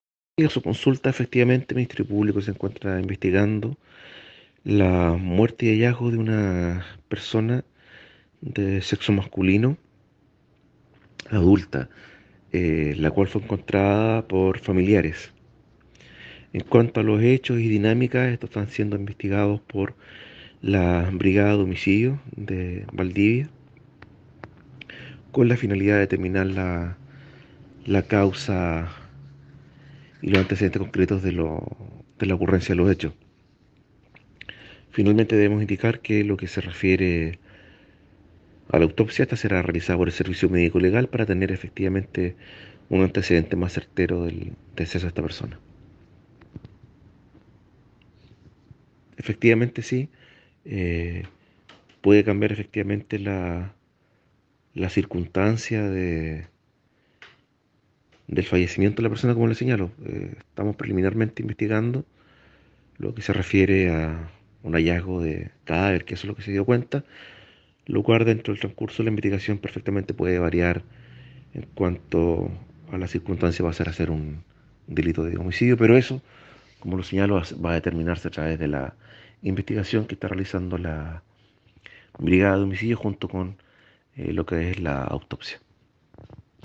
BREVES POLICIAL: Detectives de la Brigada de Homicidios concurrieron hasta un domicilio ubicado en el sector de Crucero, comuna de Rio Bueno, lugar en el cual, junto a personal del Laboratorio de Criminalística Regional Valdivia, periciaron el cuerpo de un hombre mayor de edad, concluyendo inicialmente que se trataría de un suicidio, de esta manera se aclaran las dudas que se existían respecto a la posibilidad de un homicidio de esta persona en esa comuna, la víctima fue encontrada por familiares y se investiga la circunstancia de como ocurrieron los hechos, al respecto el fiscal subrogante Rodrigo San Martín indica lo siguiente sobre la investigación que dirige la Fiscalía de Río Bueno por la muerte de un hombre en esa comuna.